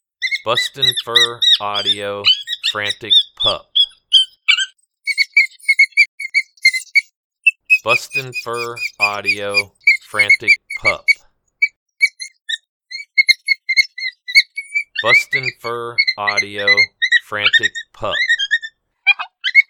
Young Coyote Pup in distress.